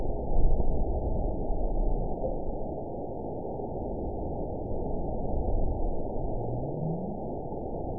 event 912517 date 03/28/22 time 15:26:35 GMT (3 years, 1 month ago) score 9.65 location TSS-AB04 detected by nrw target species NRW annotations +NRW Spectrogram: Frequency (kHz) vs. Time (s) audio not available .wav